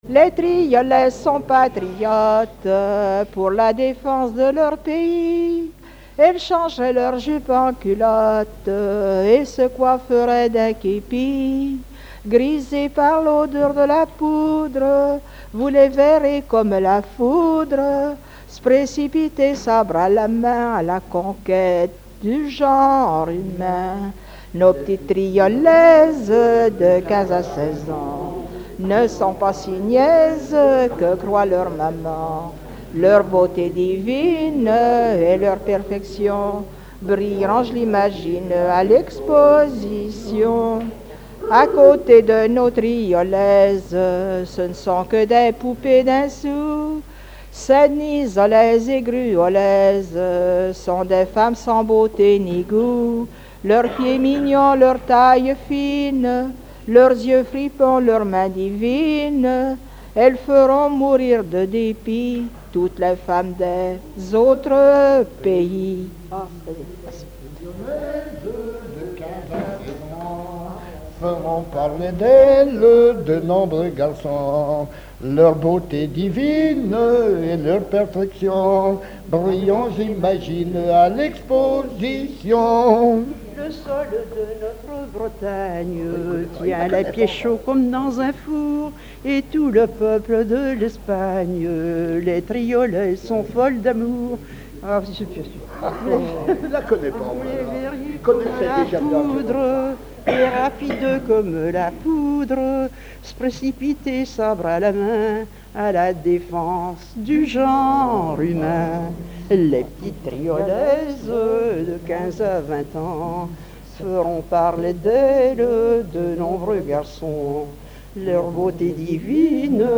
chanteuses, chanteurs, musiciens
Pièce musicale inédite